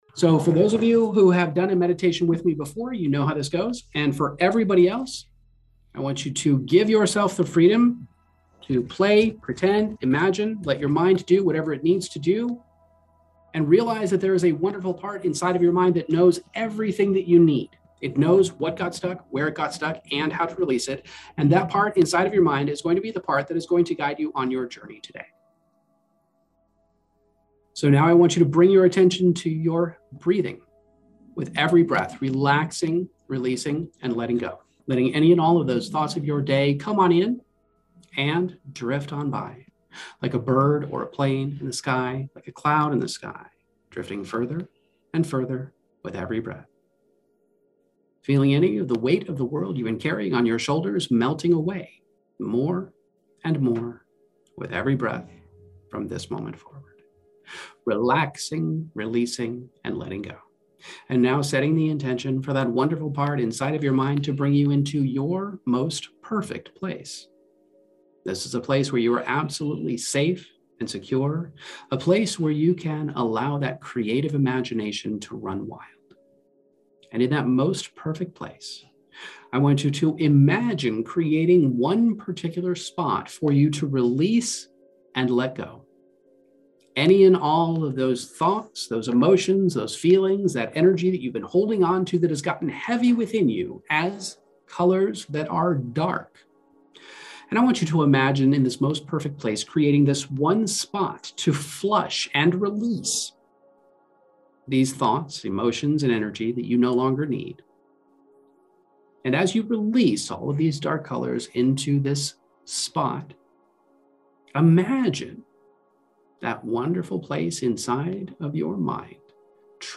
This guided hypnosis meditation is like a mental adventure that tackles the root of self-sabotage, guiding you from feeling stuck in negative self-talk and procrastination to discovering your inner wild animal strength. It starts with chilling out and letting your mind wander to those deep, dark places where you've hidden away feelings of not being good enough.
Emotional Optimization™ Meditations